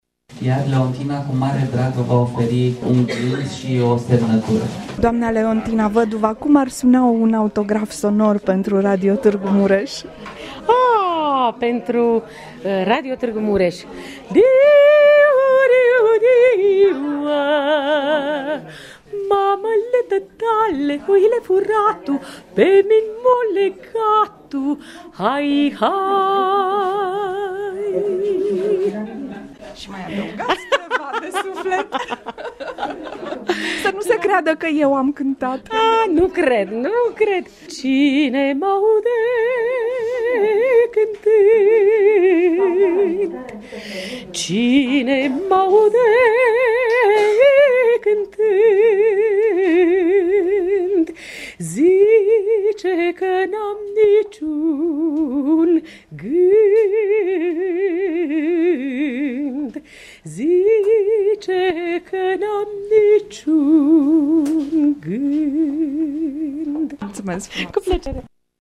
Locul în care “cărţile te privesc cu drag şi muzica te cucereşte discret”- am numit Librăria Şt.O. Iosif din Braşov-a fost, în iunie 2016, gazda unei întâlniri” de pus în buzunarul sufletului.”
I-a trecut pragul, pentru a sta de vorbă cu publicul, pentru a da autografe pe CD-ul “Verdi-Wagner.Corespondenţe” soprana de renume internațional, stabilită în Franţa, Leontina Văduva.